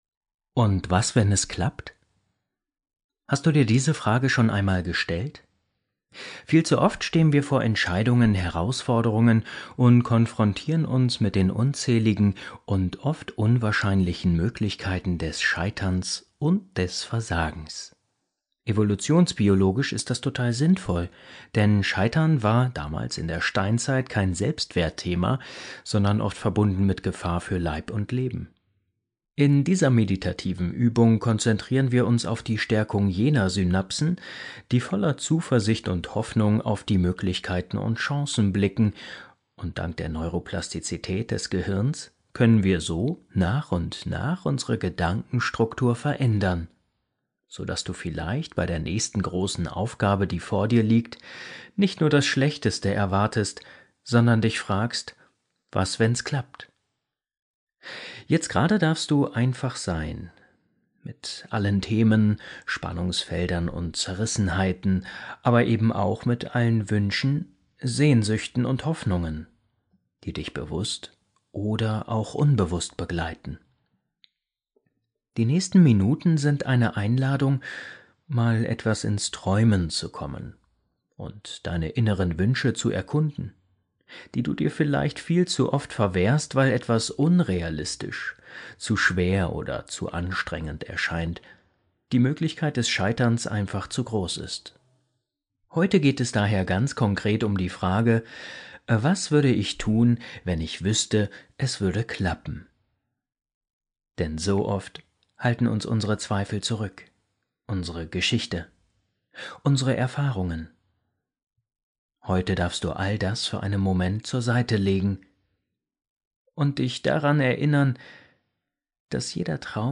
Meditation - Was wenn es klappt? Zweifel loslassen ~ Entspannungshelden – Meditationen zum Einschlafen, Traumreisen & Entspannung Podcast